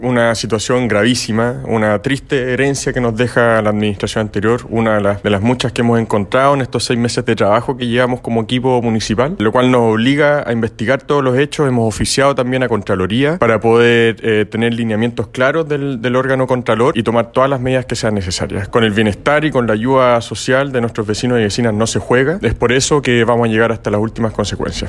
24 horas después que fuera consultado por La Radio, el actual alcalde de Puerto Varas, Tomás Gárate, expresó que llegarán a hasta las últimas instancias para aclarar lo sucedido.